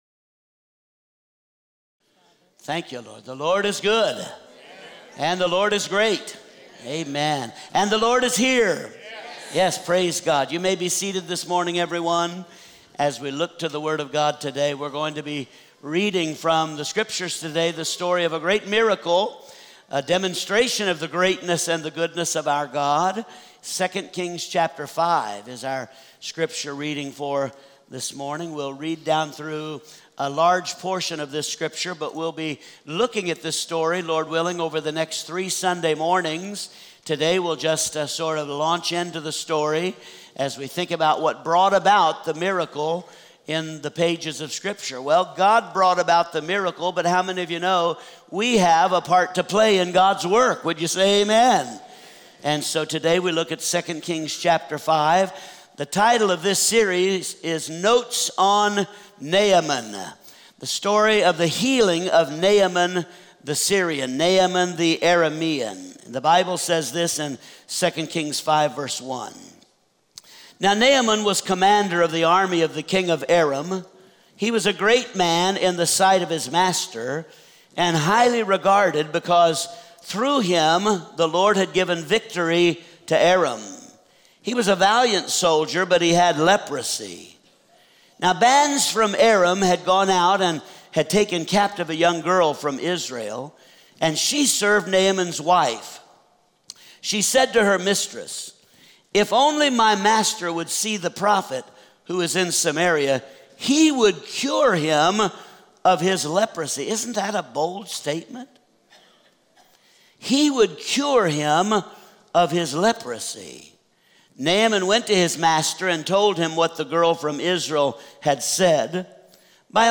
Topic: Show on Home Page, Sunday Sermons